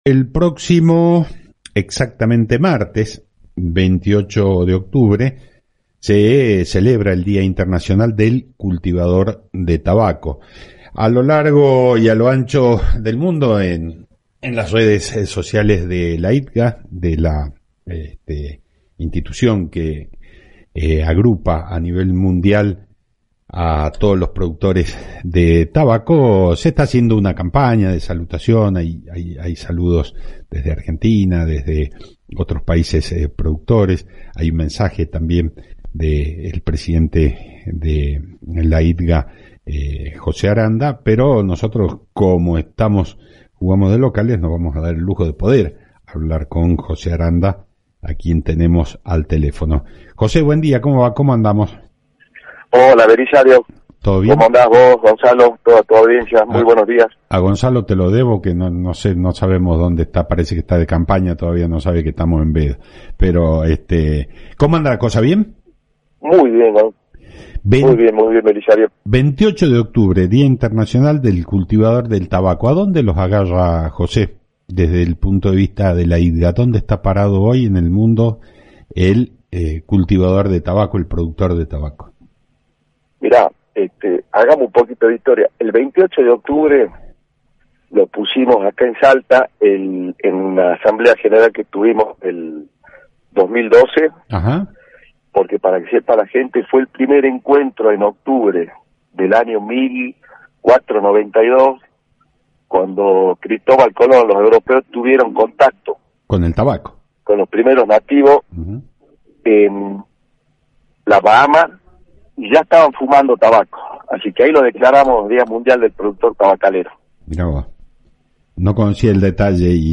En el programa Claves del Campo (AM 840 – Radio Salta)